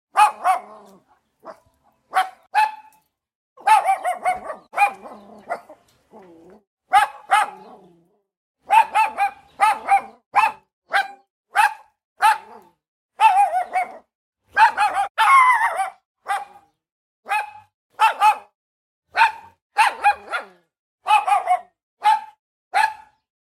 Download Small Dog Barking sound effect for free.
Small Dog Barking